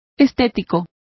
Complete with pronunciation of the translation of aesthetic.